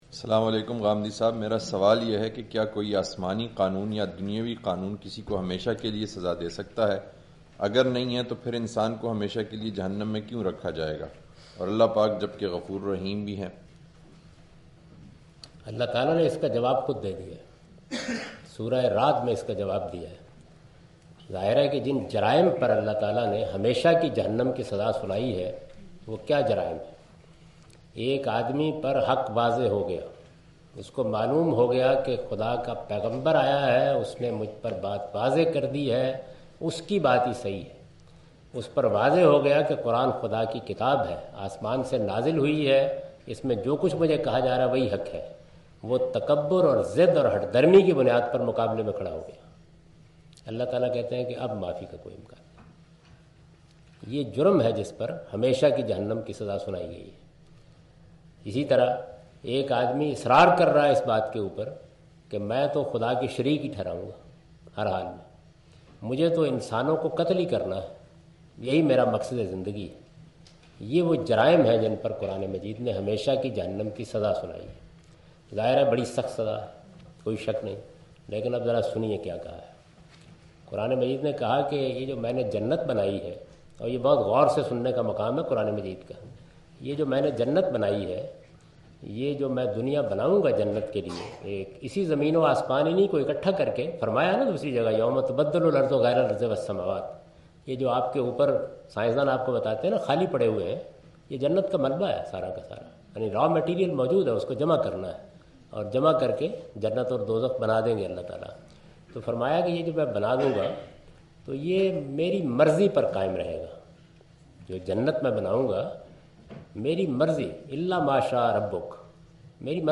Javed Ahmad Ghamidi answer the question about "eternal punishment and mercy of God" during his visit to Queen Mary University of London UK in March 13, 2016.
جاوید احمد صاحب غامدی اپنے دورہ برطانیہ 2016 کےدوران کوئین میری یونیورسٹی اف لندن میں "ابدی عذاب اور رحمت خداوندی" سے متعلق ایک سوال کا جواب دے رہے ہیں۔